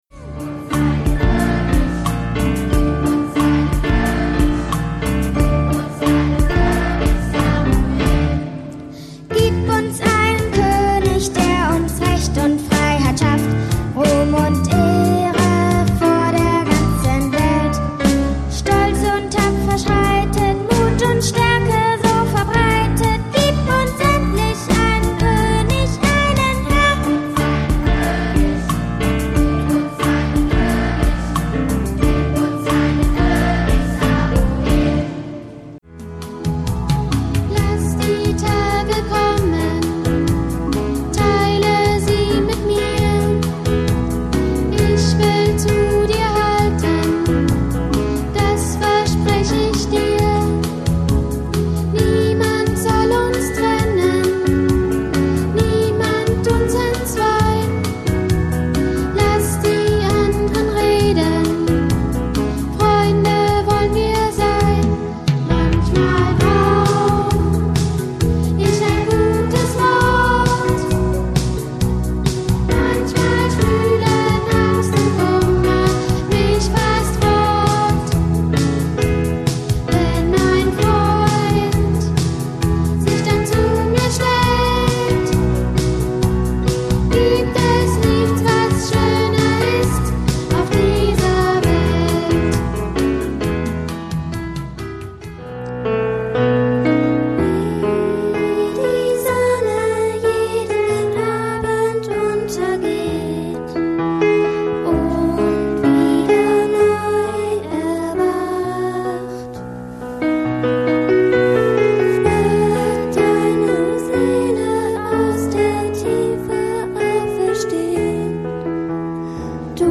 Besetzung: S/A, Soli, Orff, Perc., Pno. (variabel)